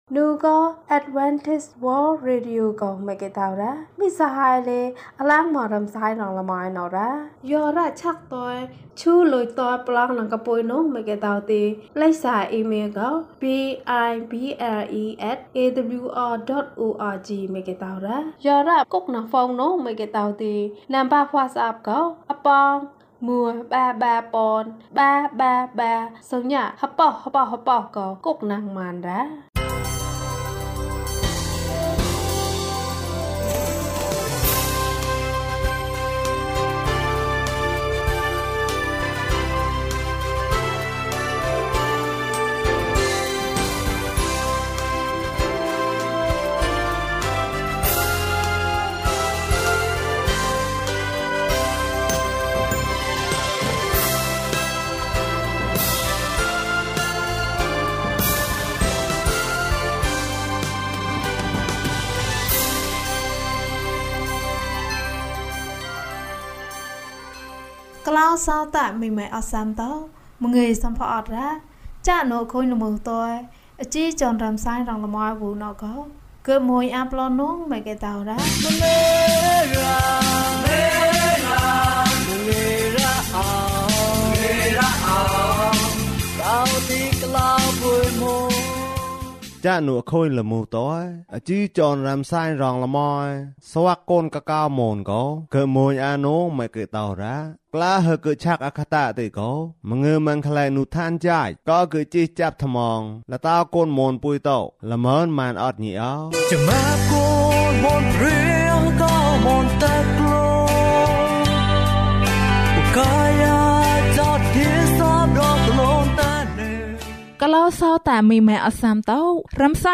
လူငယ်များအတွက် သတင်းစကား။၀၁ ကျန်းမာခြင်အးကြောင်းအရာ။ ဓမ္မသီချင်း။ တရားဒေသနာ။